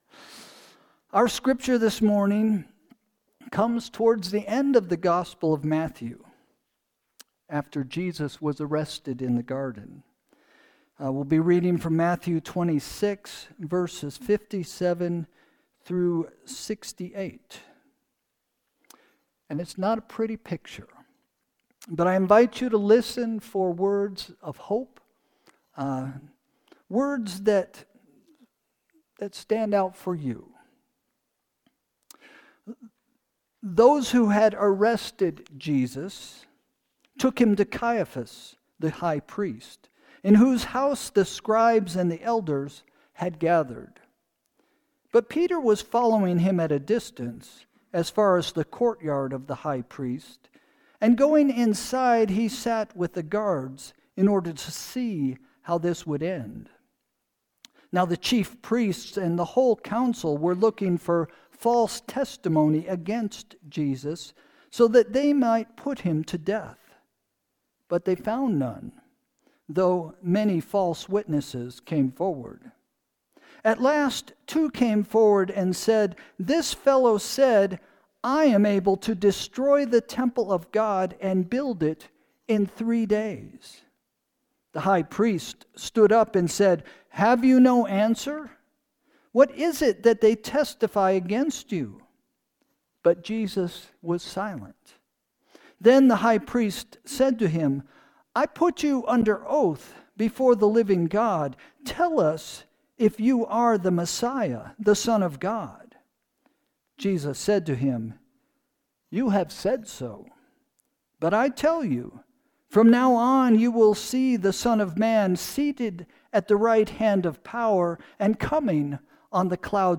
Sermon – February 23, 2025 – “Spit Upon” – First Christian Church